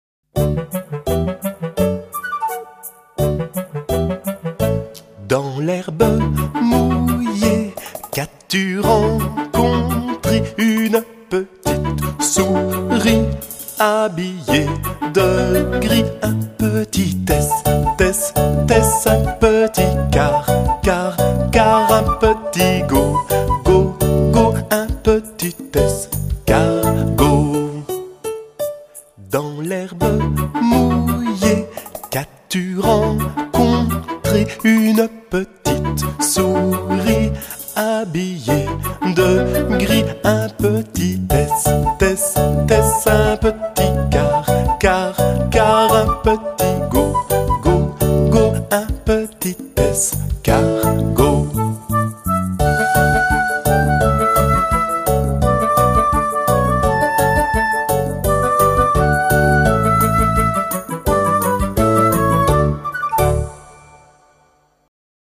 Une petite comptine